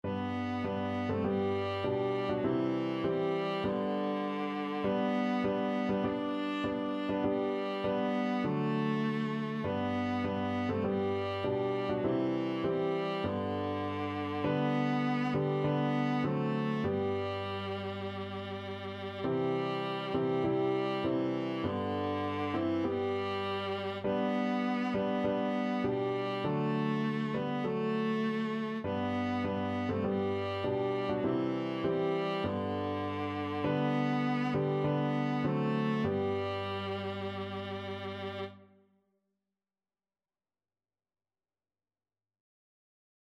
4/4 (View more 4/4 Music)
Classical (View more Classical Viola Music)